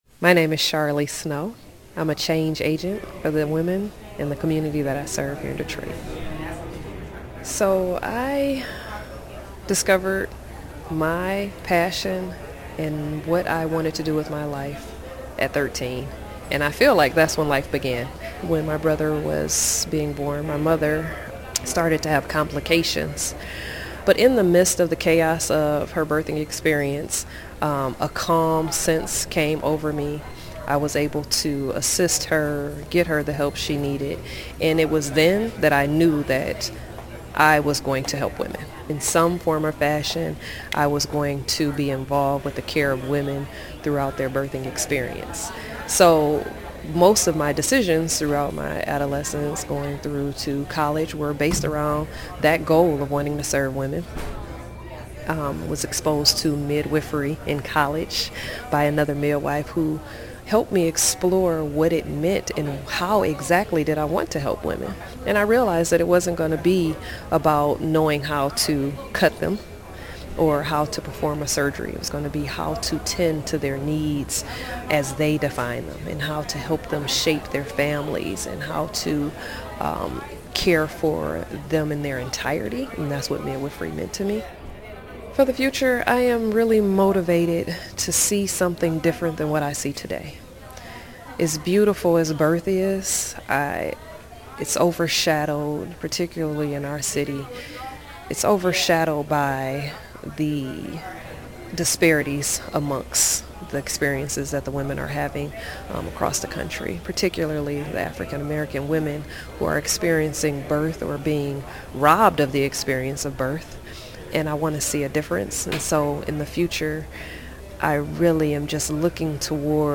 Oral histories